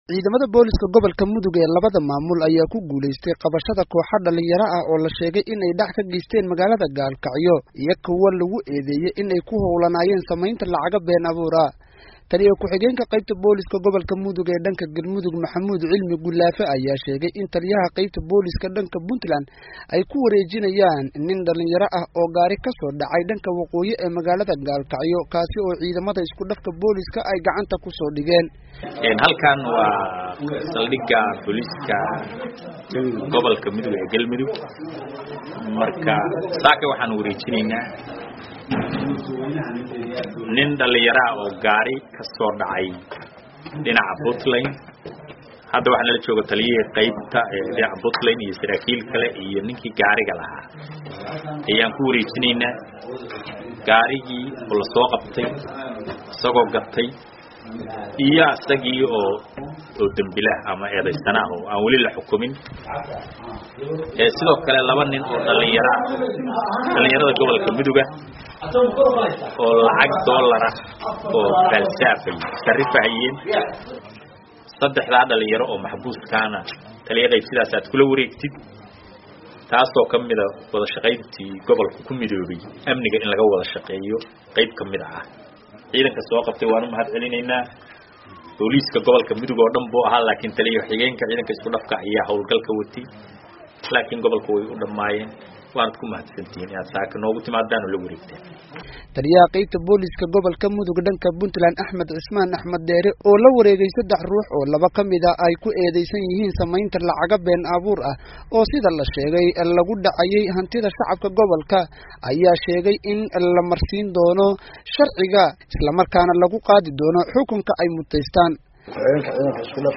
warbixintan ka soo diray magaalada Gaalkacyo